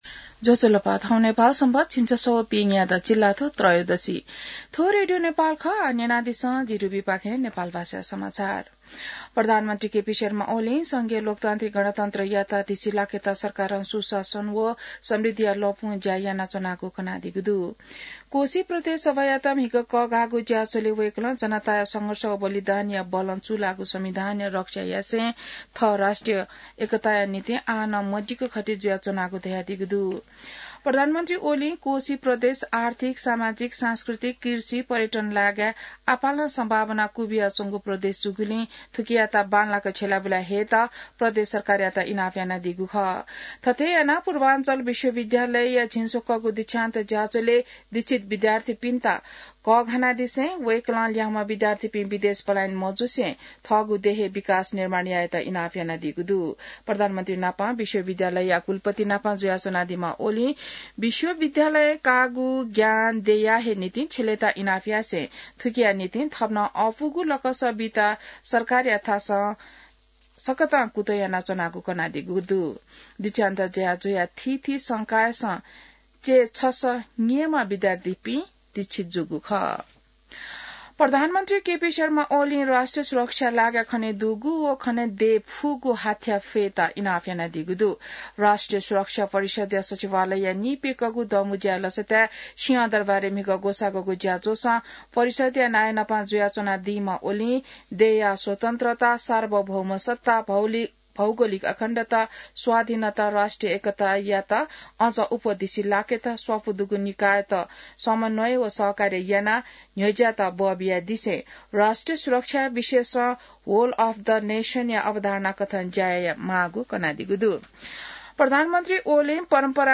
नेपाल भाषामा समाचार : २९ फागुन , २०८१